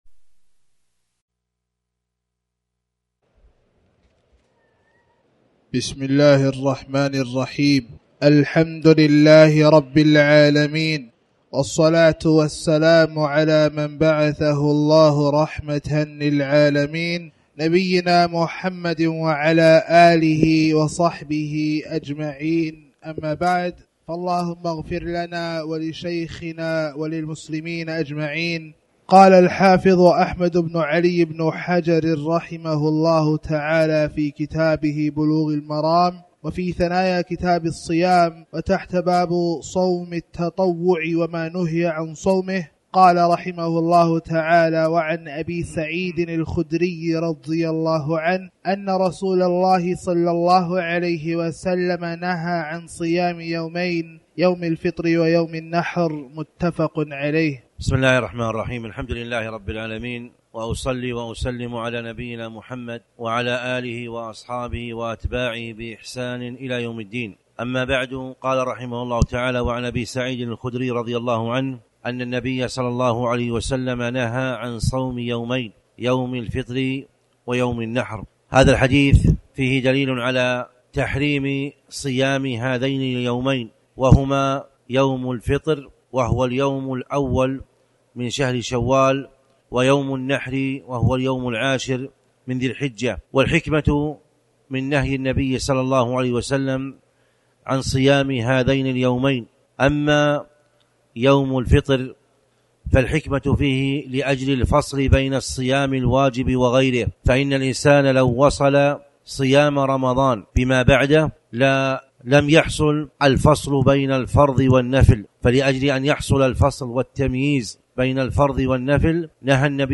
تاريخ النشر ٢٥ شعبان ١٤٣٩ هـ المكان: المسجد الحرام الشيخ